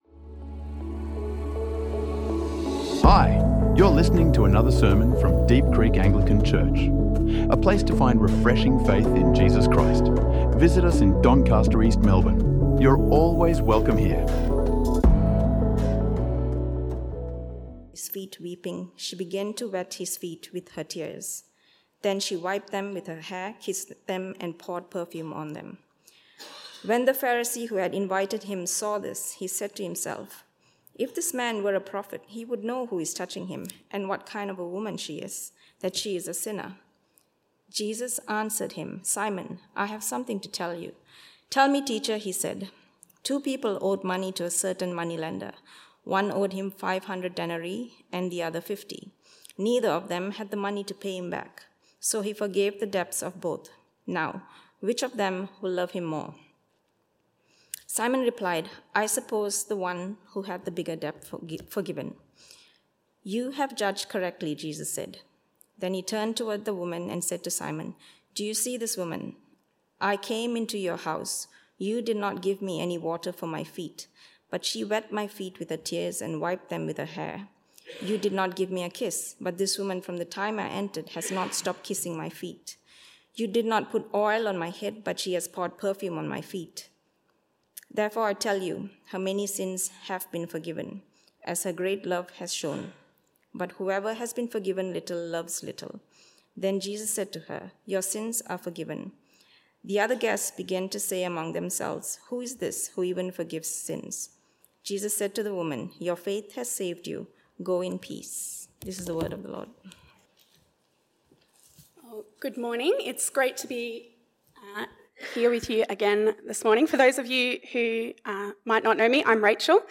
God is AmongYou in Hospitality | Sermons | Deep Creek Anglican Church